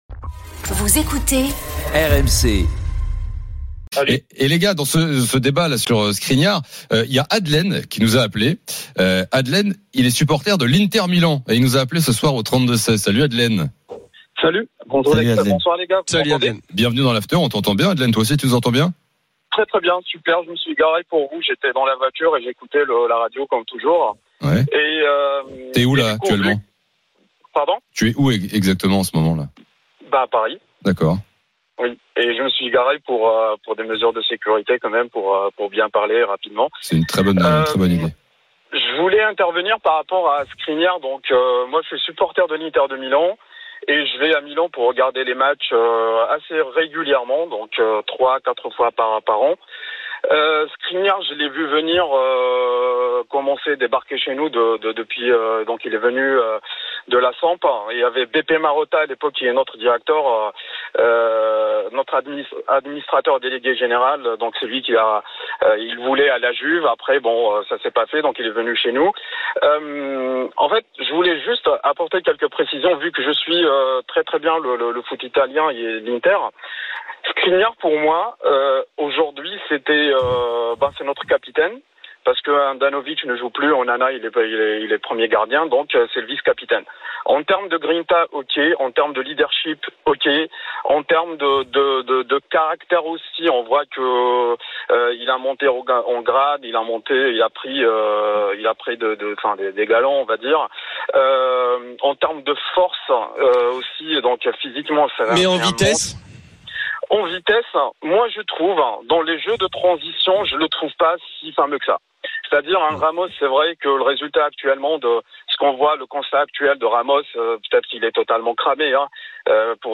L’After foot, c’est LE show d’après-match et surtout la référence des fans de football depuis 15 ans !
RMC est une radio généraliste, essentiellement axée sur l'actualité et sur l'interactivité avec les auditeurs, dans un format 100% parlé, inédit en France.